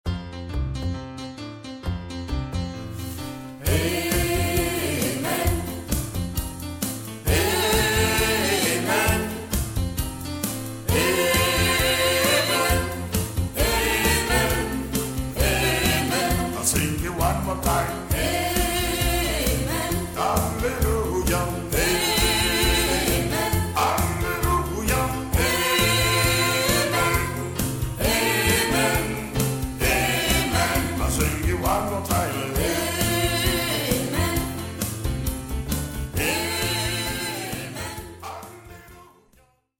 Le Gospel Glory se produit souvent en concert, et s’est spécialisé aussi dans l’animation de toutes cérémonies ou événements :
avec un répertoire liturgique, negro spiritual et gospel